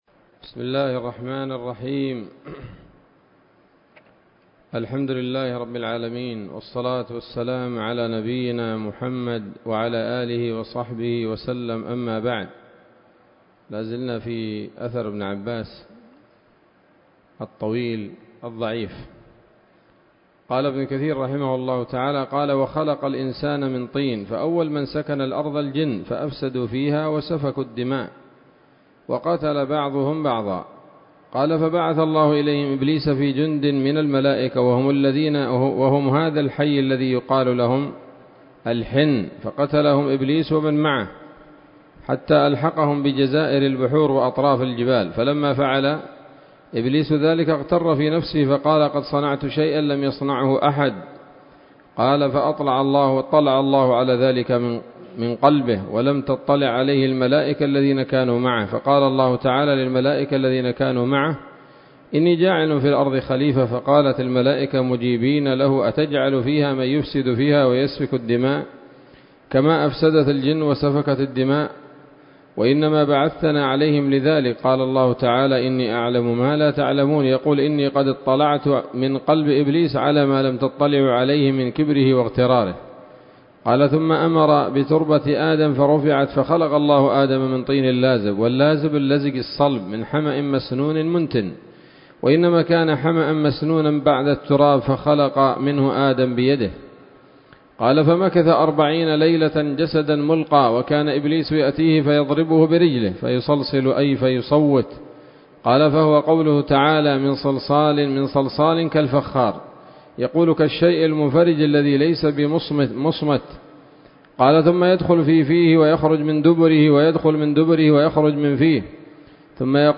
الدرس الثامن والثلاثون من سورة البقرة من تفسير ابن كثير رحمه الله تعالى